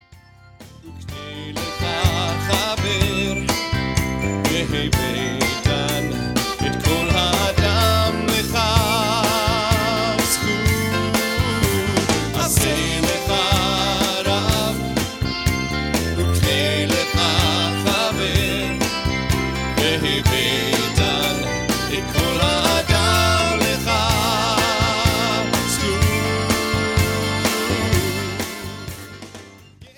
spiritual and energetic songs